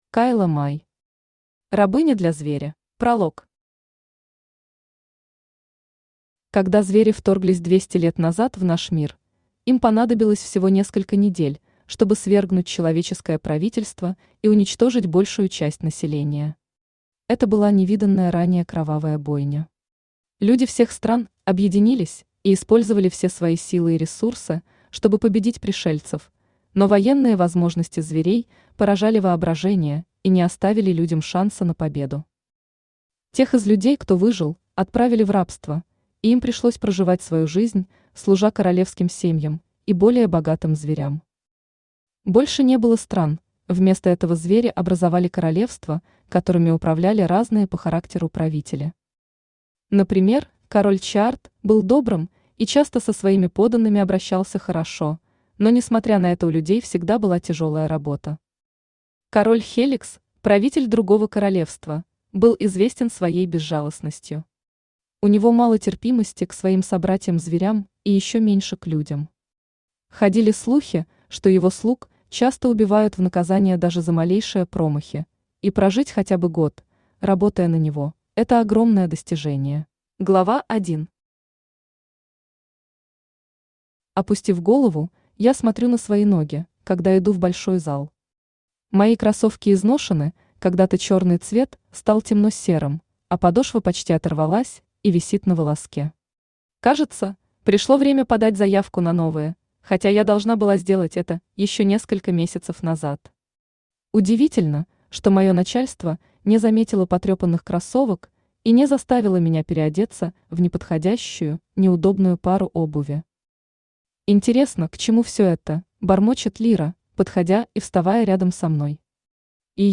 Аудиокнига Рабыня для Зверя | Библиотека аудиокниг
Aудиокнига Рабыня для Зверя Автор Кайла Май Читает аудиокнигу Авточтец ЛитРес.